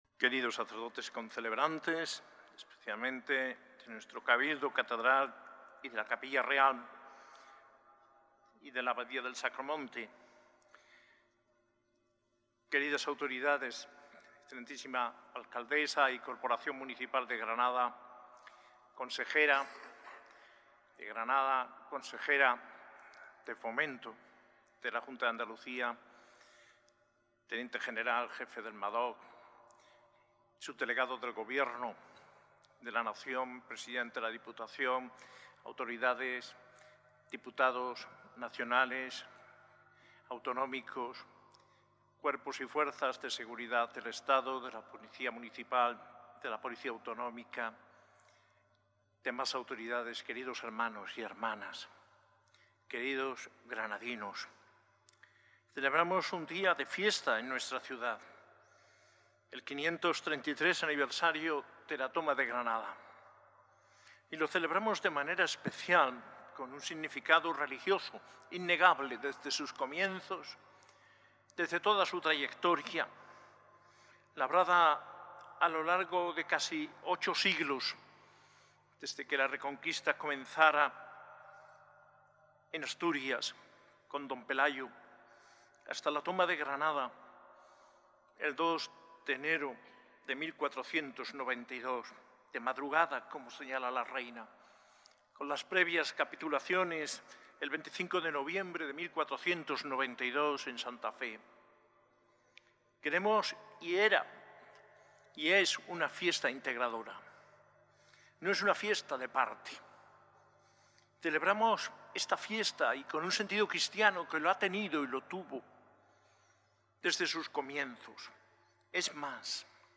Homilía de la Eucaristía en el día de la Toma de Granada, el 2 de enero de 2025.